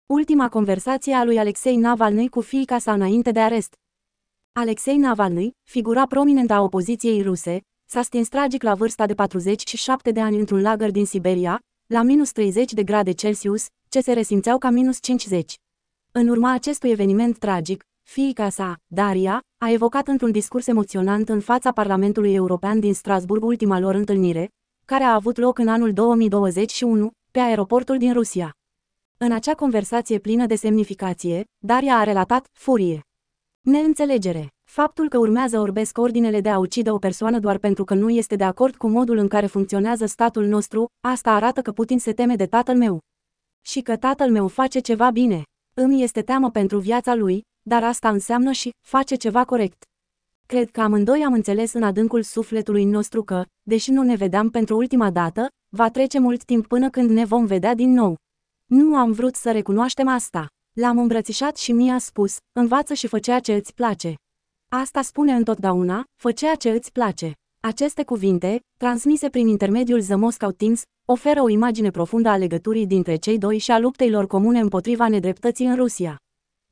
Get in touch with us ASCULTĂ ARTICOLUL Alexei Navalnîi, figura prominentă a opoziției ruse, s-a stins tragic la vârsta de 47 de ani într-un lagăr din Siberia, la -30 de grade Celsius, ce se resimțeau ca -50.